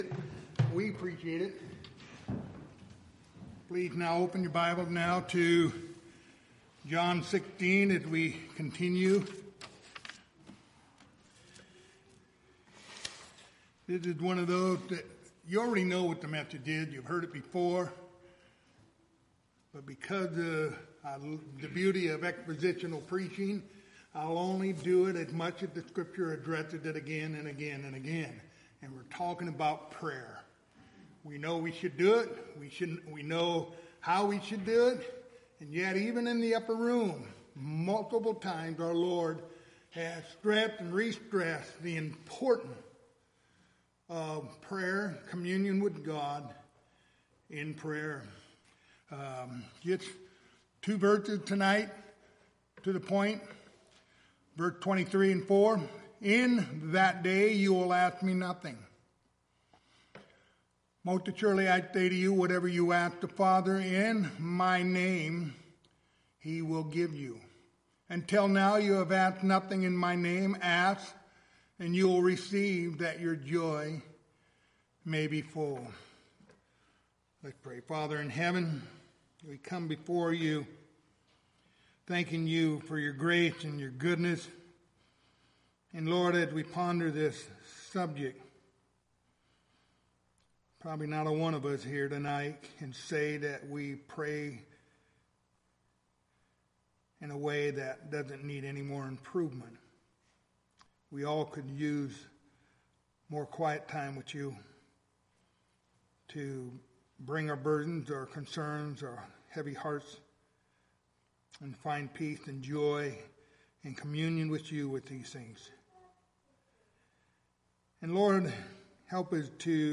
Preacher
Passage: John 16:23-24 Service Type: Wednesday Evening